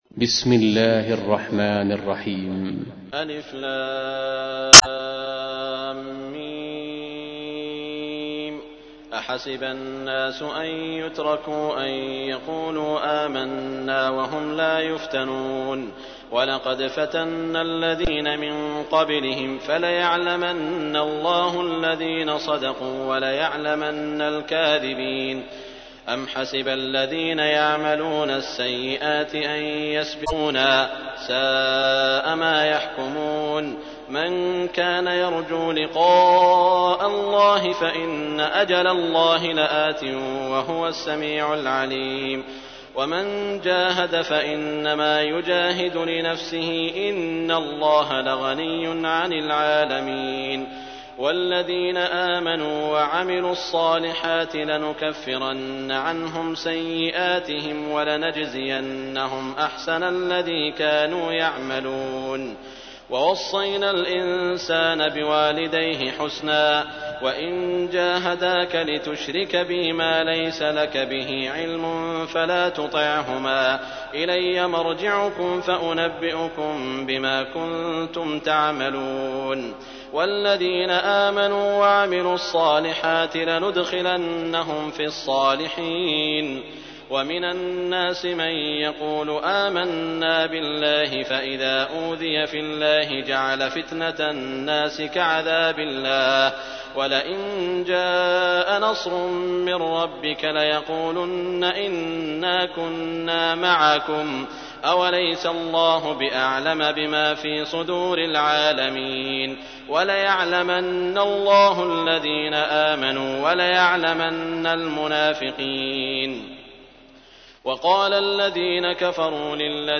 تحميل : 29. سورة العنكبوت / القارئ سعود الشريم / القرآن الكريم / موقع يا حسين